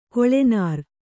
♪ koḷe nār